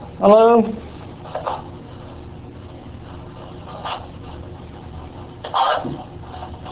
On October 11-12, 2014 the Public Information Office and the Morton Theatre staff brought in a team from Ghosts of Georgia Paranormal Investigations to investigate strange occurrences that have been reported by numerous people throughout the building's history.
Electronic Voice Phenomena (Unidentified audio)